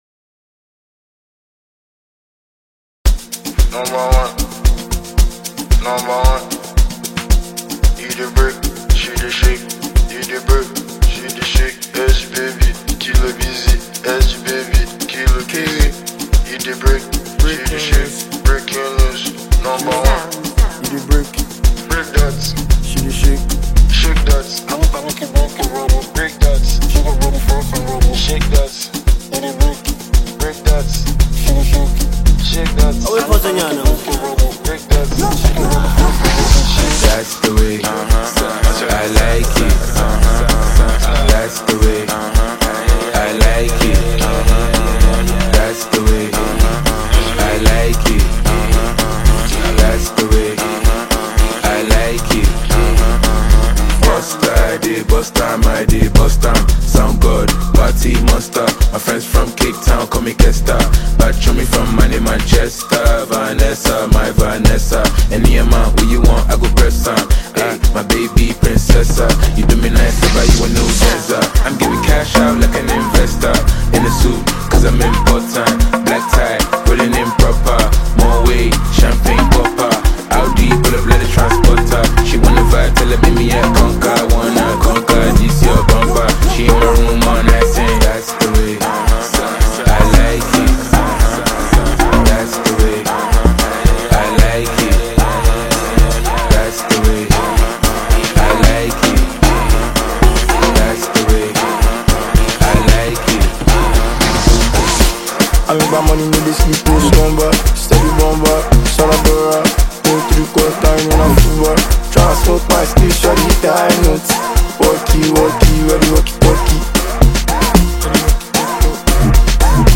Fresh vibes, clean energy, and nothing but pure sauce!
smooth, addictive sound